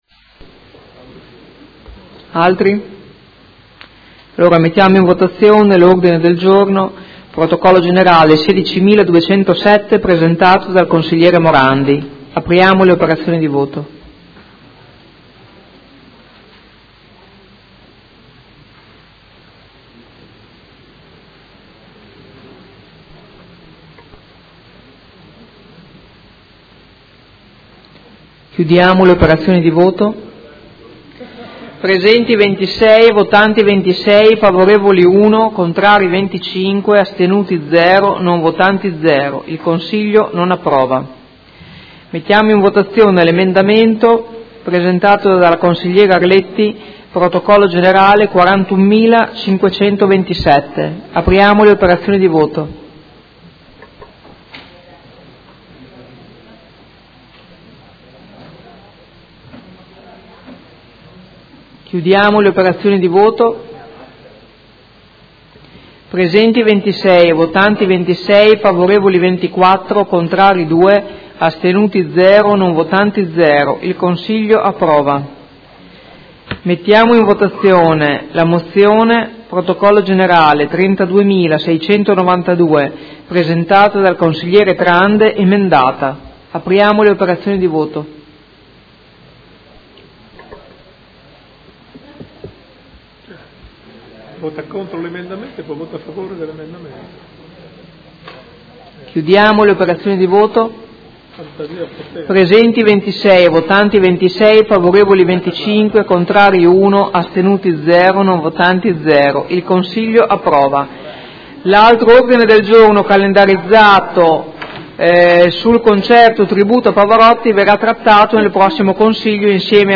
Presidente — Sito Audio Consiglio Comunale
Seduta del 16/03/2017.